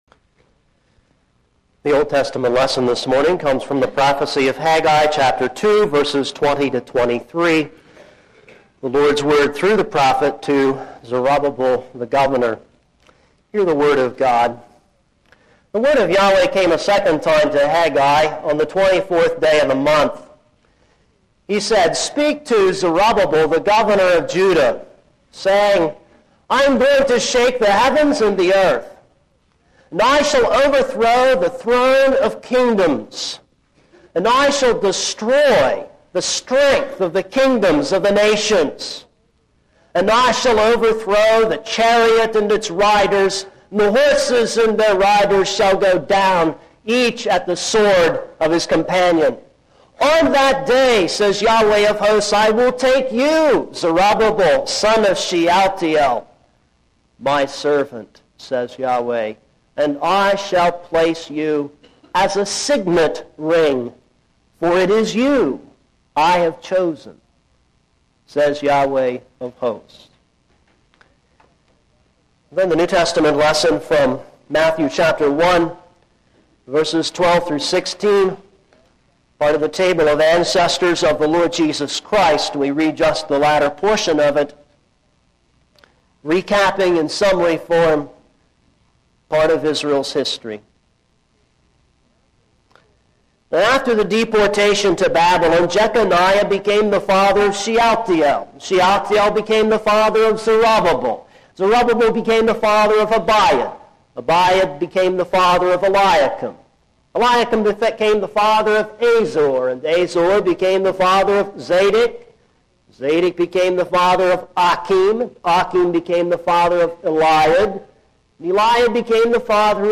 This is a sermon on Haggai 2:20-23.